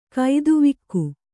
♪ kaiduvikku